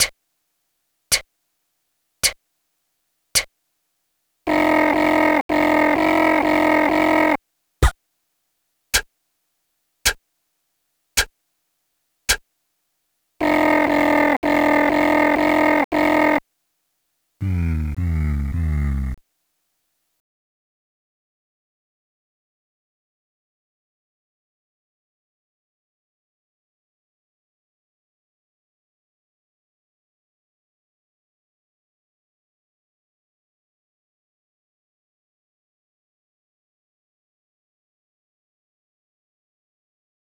phonetic typewriter aufstehen
eine alltagsszene als microdrama. vielleicht bemüht sich ihre lautmalerei etwas zu sehr um naturalistische abbildung realer geräusche - wenn sie phont verwenden wollen, um reale geräusche zu reproduzieren, sollten sie die limitierungen des systems als positive gestaltungselemente nutzen. eine strategie dafür wäre die übertreibung oder die ironisierung dieser beschränkungen - so, wie sie es ganz ausgezeichnet in ihrem fische-text gemacht haben.